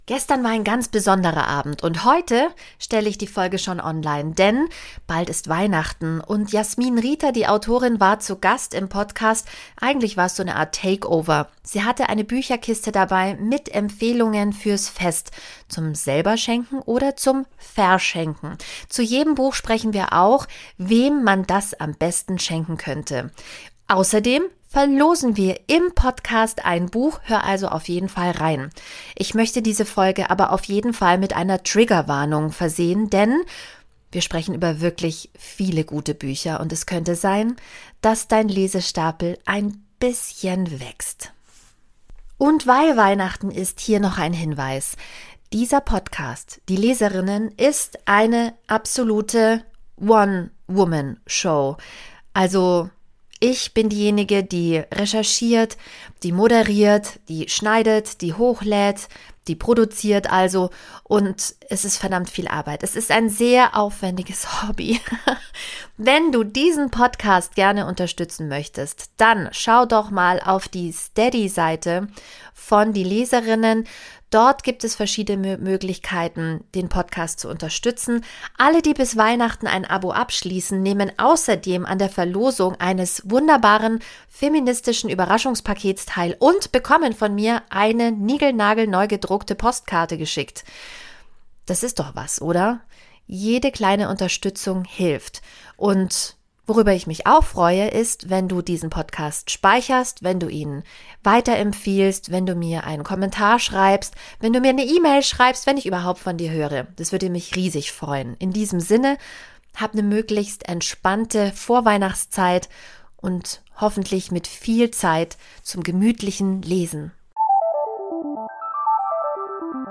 Das ist gut, denn ich war bei dieser Live-Aufzeichnung so erkältet, dass ich immer wieder husten musste - vielleicht hörst du mich im Hintergrund manchmal röcheln.